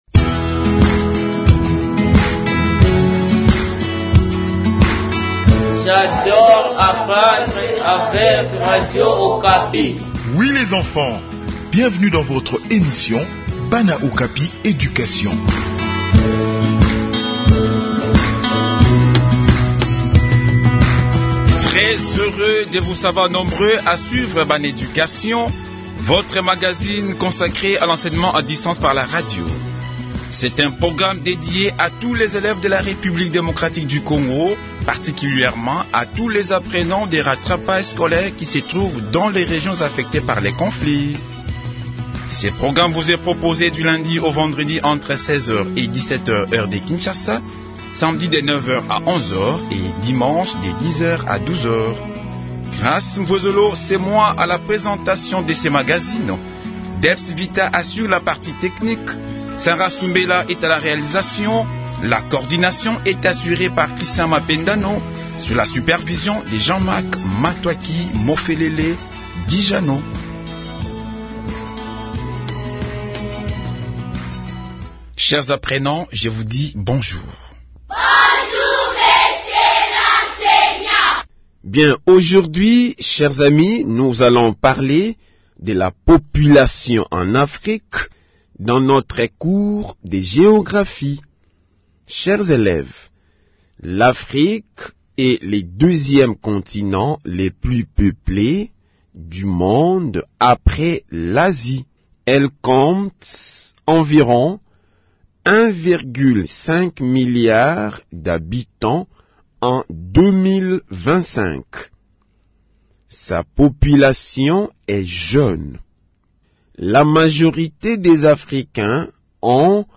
Enseignement à distance : Leçon sur la population d’Afrique